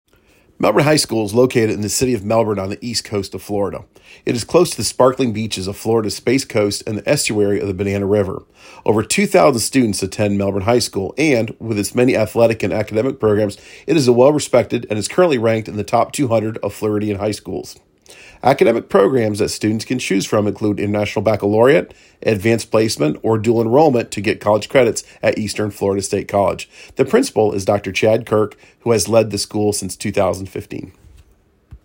1. ** Baccalaureate is pronounced BACK-A-LOR-E-IT
HERE is my audio file reading the speech